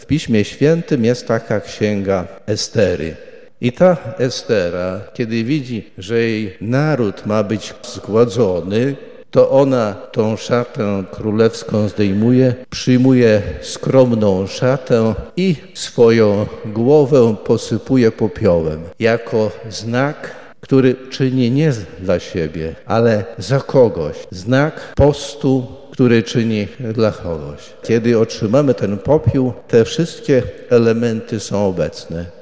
Biskup Janusz Stepnowski przed południem przewodniczył MszyŚwiętej w łomżyńskiej katedrze.
W swojej homilii mówił między innymi o znaczeniu popiołu.